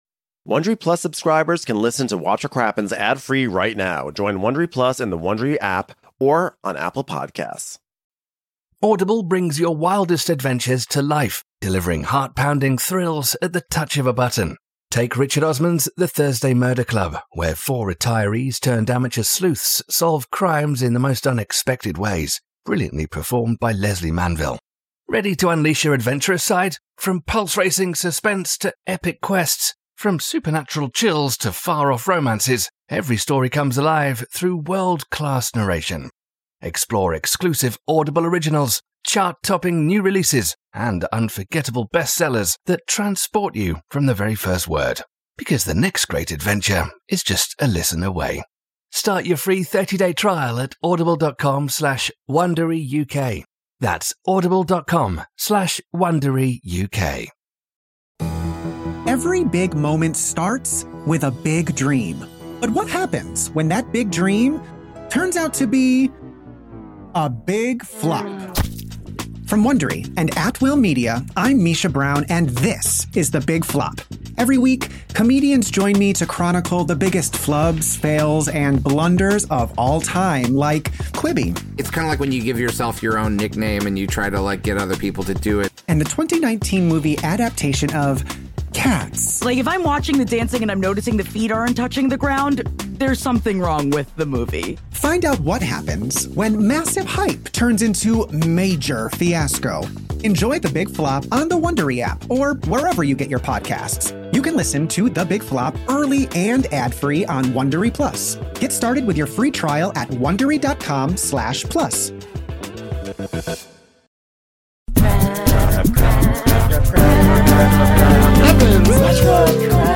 and the woman’s impressions are ON POINT, people!